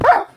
wolf_hurt2.ogg